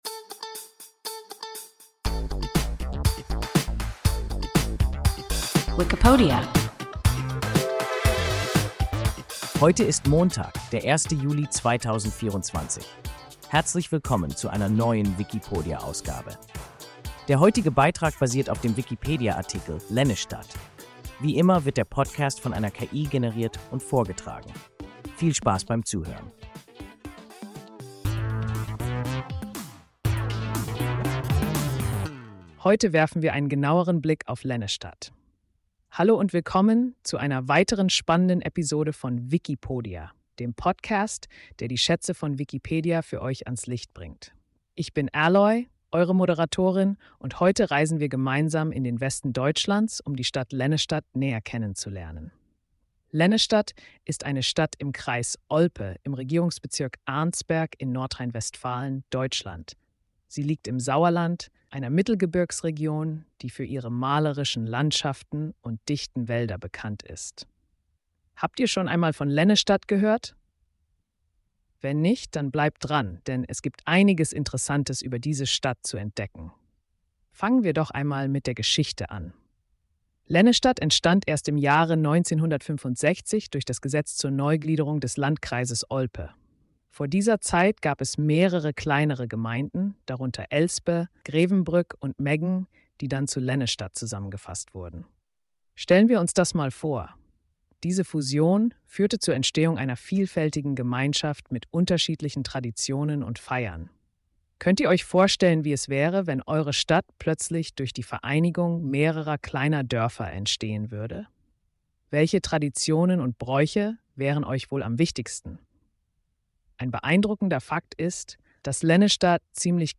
Lennestadt – WIKIPODIA – ein KI Podcast